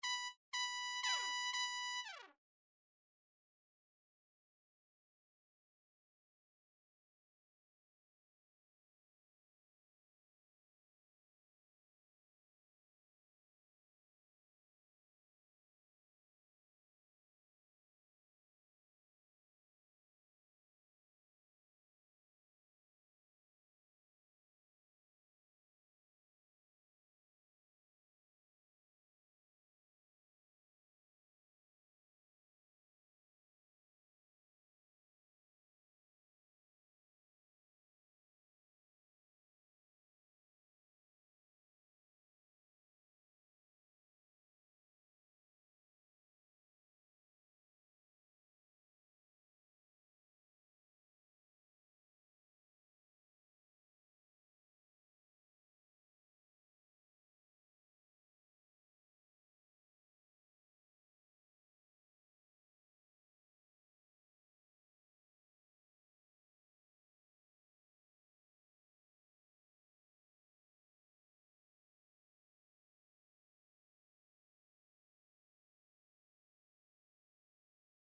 They play consistently in the file you just sent me:
Either it is not the same version of JABB (your ARIA players loaded empty so I had to manually load the JABB trumpet to hear it), and the version of JABB that you have behaves differently.